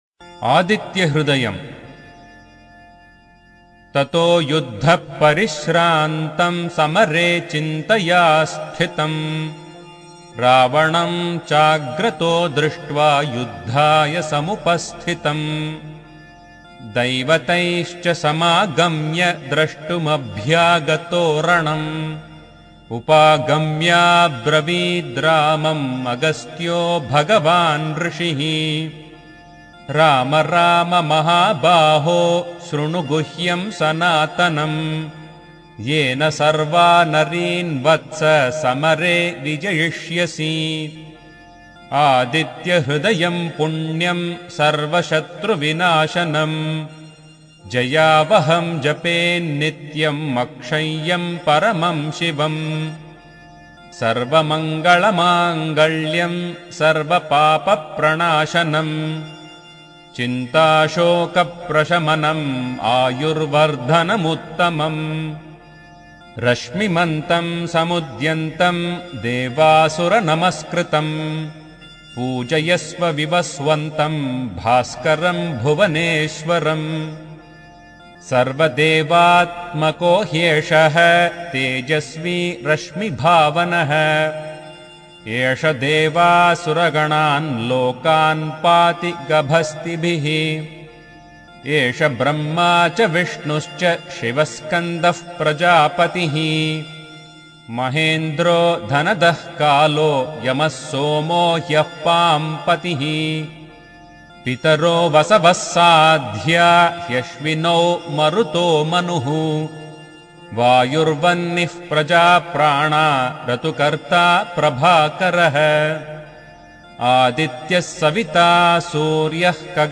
This sacred Hindu hymn, given to Lord Rama to secure victory over Ravana, became my father’s "kavacha" (armor), instilling unwavering confidence and dispelling darkness. Today, I continue this legacy, listening to the hymn in a voice that resembles his, honoring his belief that mental clarity and physical strength are aligned with the sun's universal energy.